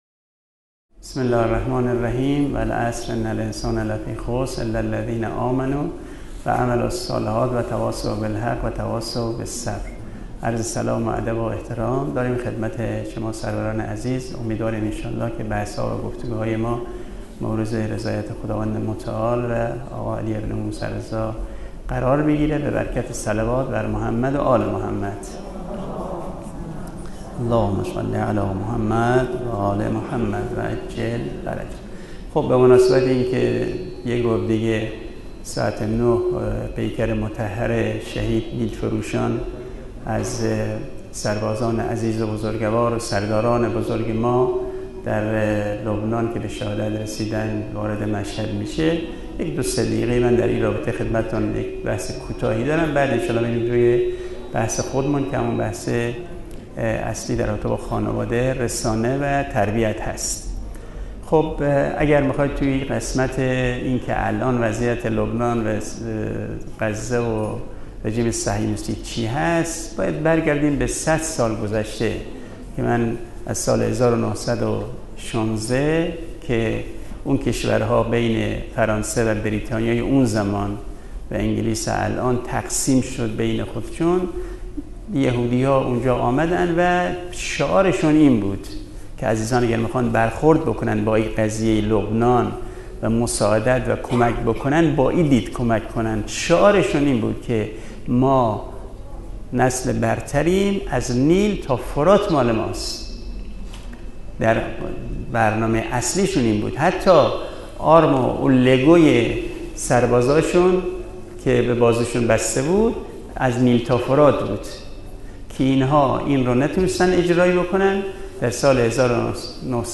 کارگاه تربیت فرزند
حرم مطهر رضوی